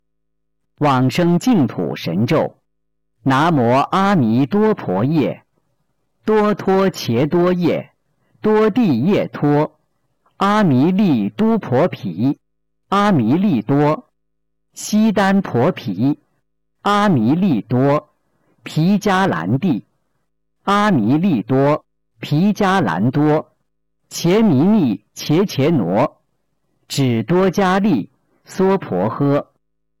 005《往生咒》教念男声